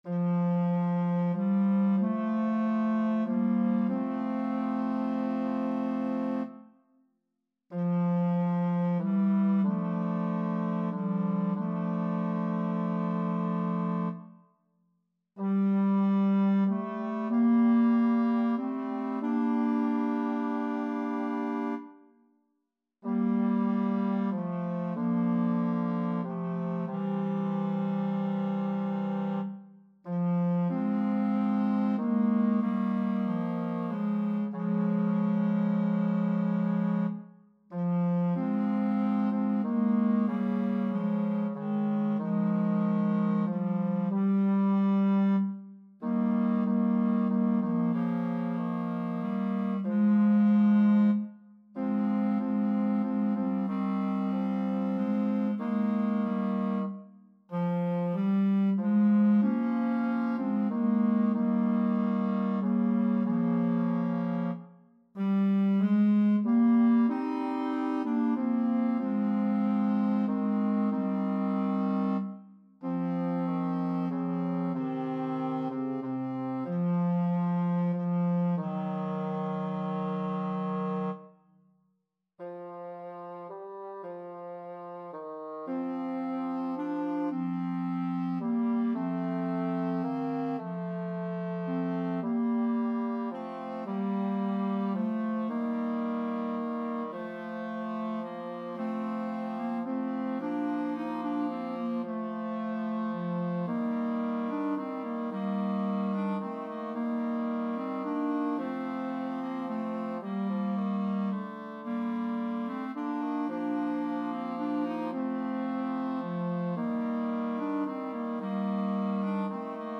Number of voices: 3vv Voicing: TTB Genre: Sacred, Motet
Language: Latin Instruments: A cappella